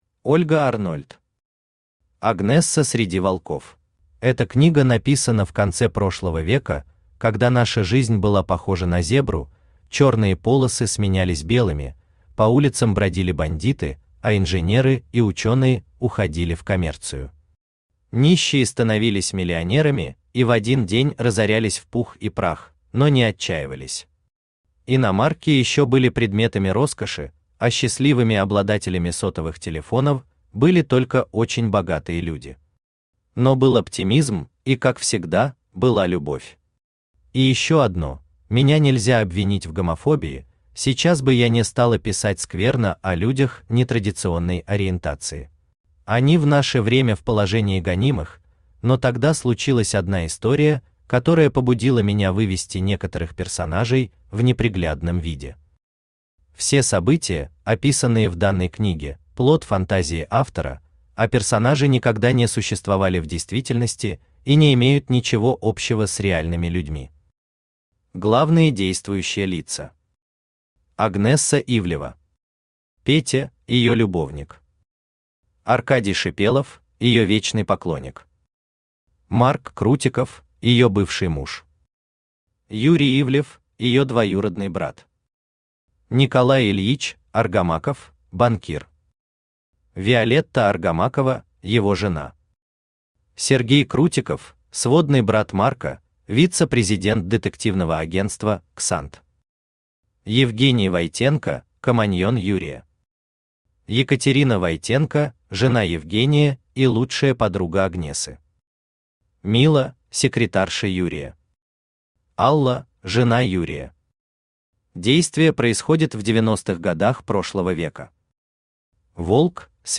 Aудиокнига Агнесса среди волков Автор Ольга Арнольд Читает аудиокнигу Авточтец ЛитРес.